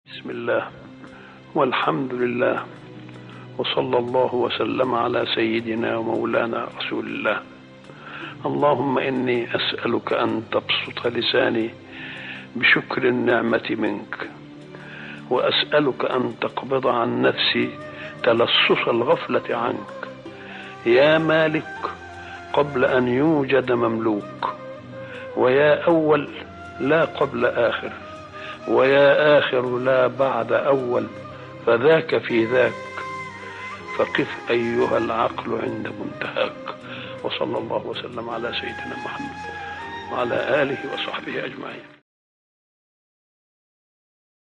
دعاء خاشع مليء بالحمد والثناء على الله والصلاة على رسوله، يتضمن سؤالاً للتخلص من الغفلة وشكر النعمة. يُظهر الدعاء تذللاً بين يدي الخالق والاستعانة به وحده، مع إشارة إلى عظمة العقل المؤمن.